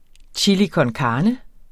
Udtale [ tjilikʌnˈkɑːnə ]